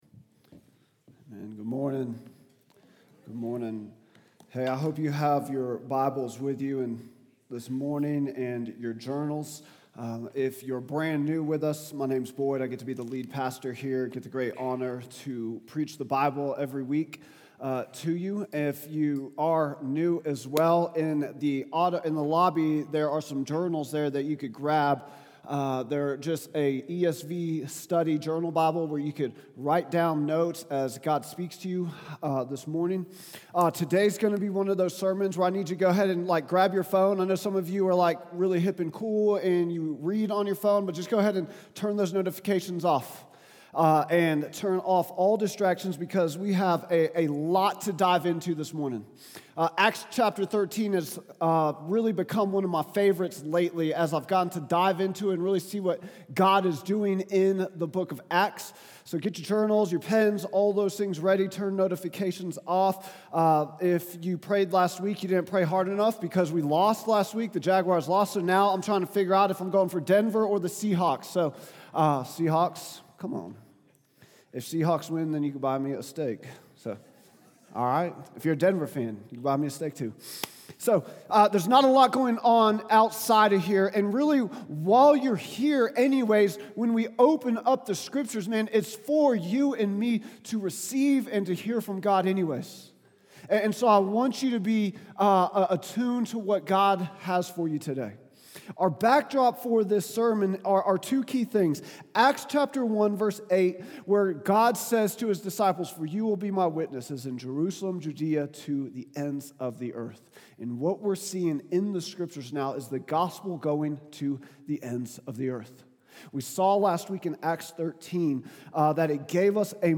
Vision & Values Meet Our Team Statement of Faith Sermons Contact Us Give To the Ends of the Earth | Acts 13:13-43 January 18, 2026